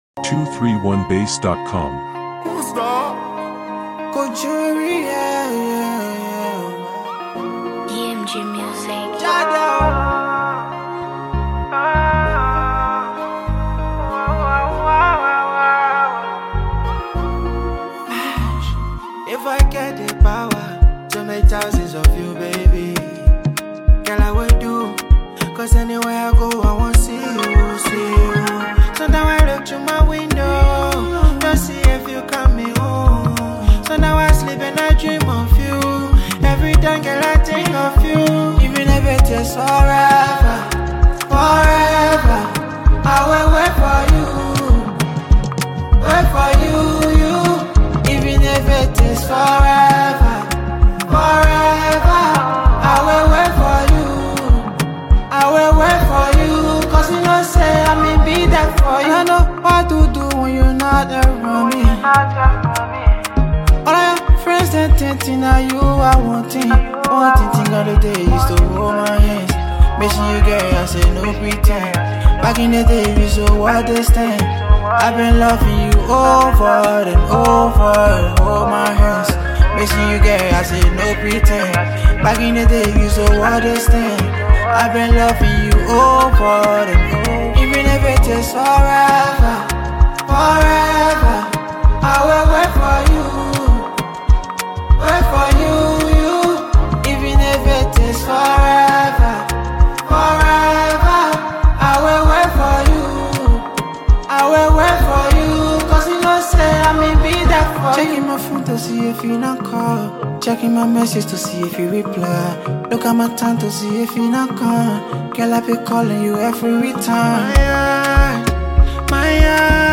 Afro pop
romantic anthem